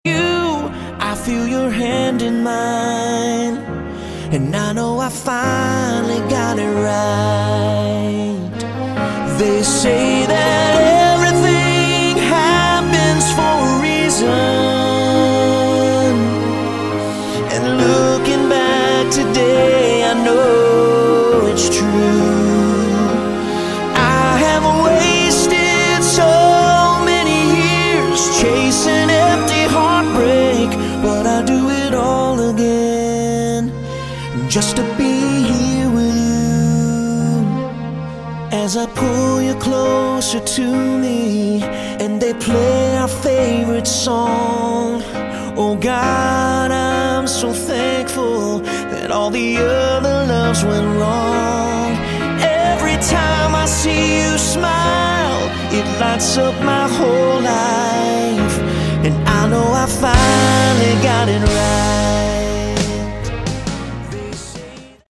Category: AOR / Melodic Rock
vocals, keyboards, guitars